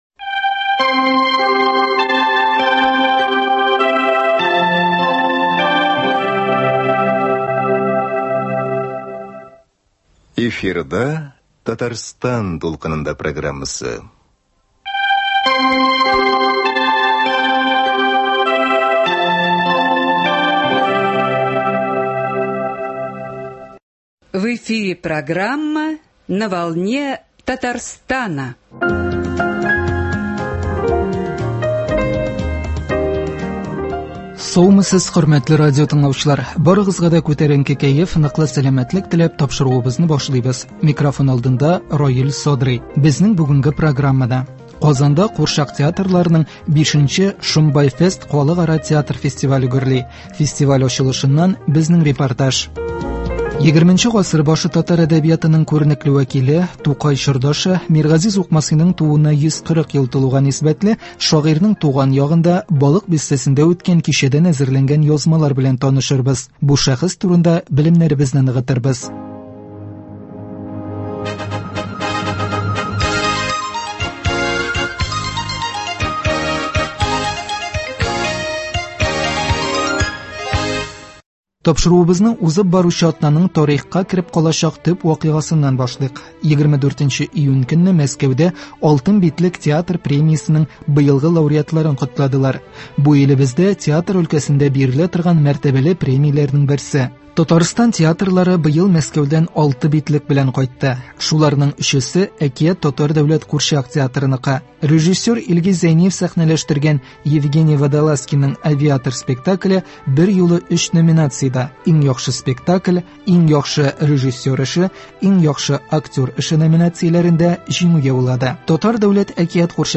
1. Курчак театрларының "Шомбай фест" V Халыкара театр фестивале ачылышыннан репортаж. 2.
Туган төбәгендәге чаралардан репортаж.